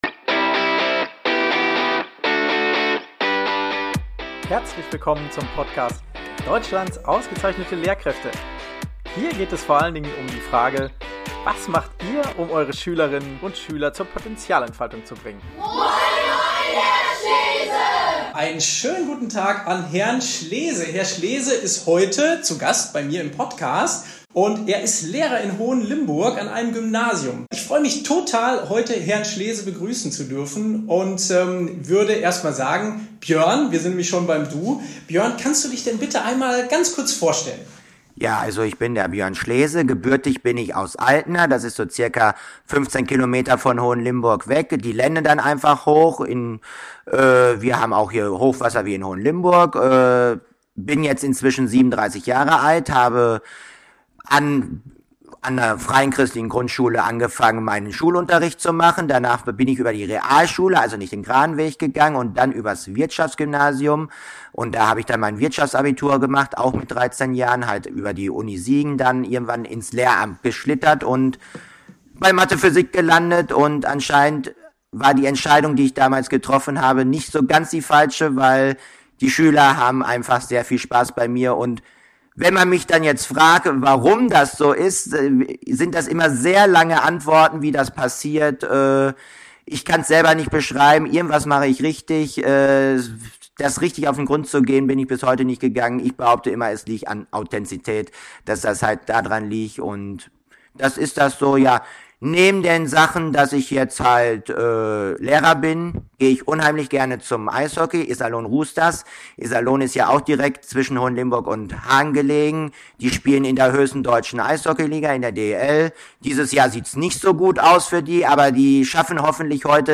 PS: Bitte entschuldigt die Tonqualität. Leider war mein Hauptmikro ausgefallen.